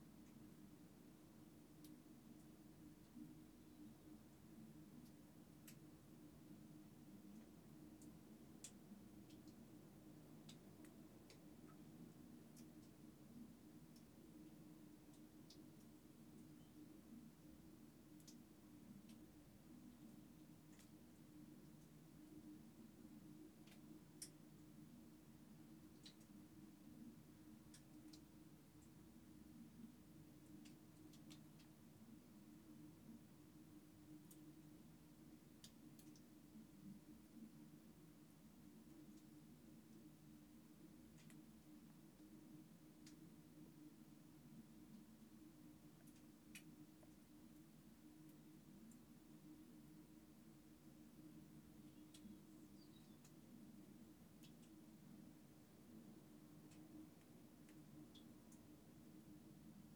February 2013, Isle of Skye, Sound Portals
The walk between the two sites of Uamh an Ard Achadh and Uamh an t-sìol/Uamh an t-sìtheanach is peppered with little sound portals through which you can hear the echo of water running through caves and cavities below.